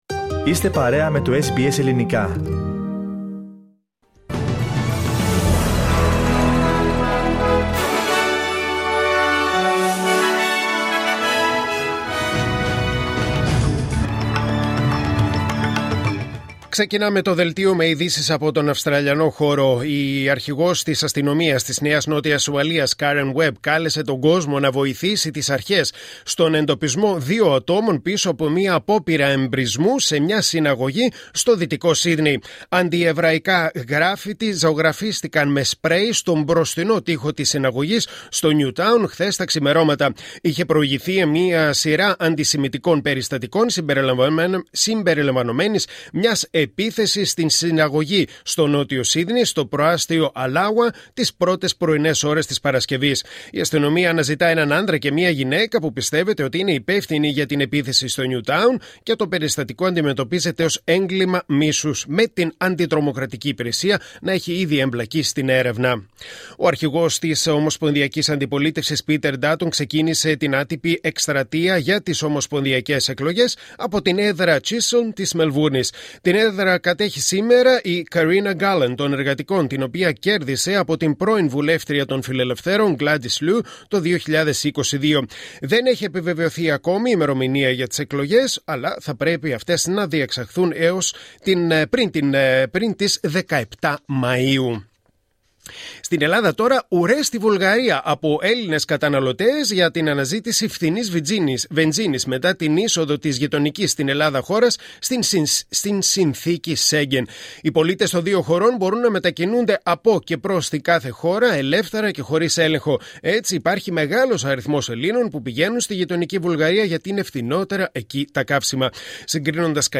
Δελτίο Ειδήσεων Κυριακή 12 Ιανουαρίου 2025